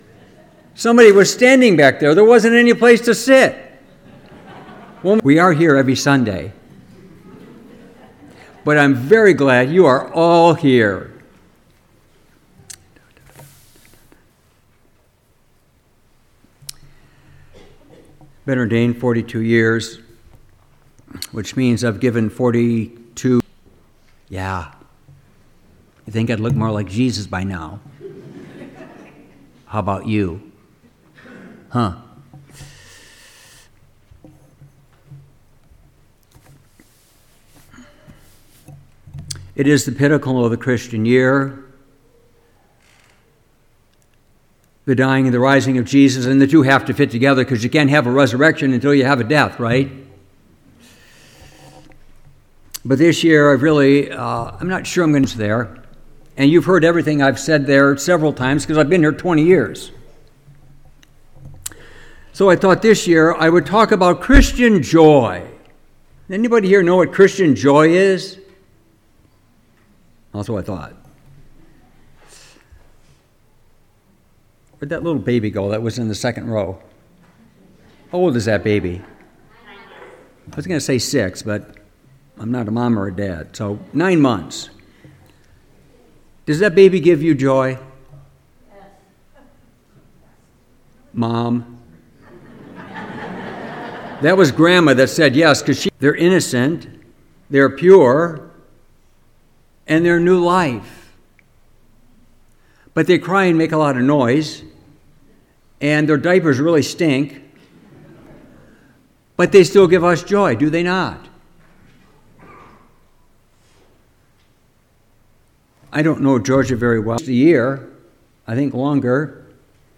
Recent Sermons
Homily-Easter-Sunday26.mp3